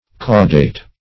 Caudate \Cau"date\, Caudated \Cau"da*ted\ a. [L. cauda tail.]